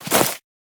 Sfx_creature_snowstalkerbaby_walk_03.ogg